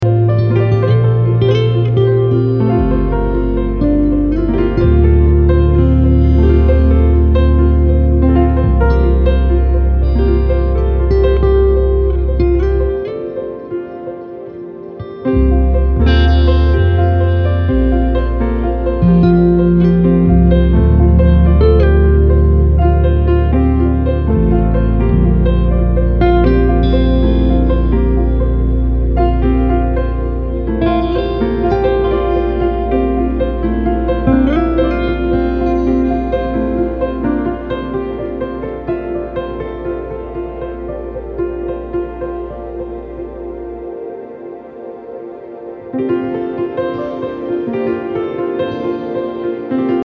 Ambient, Drone >
Post Classical >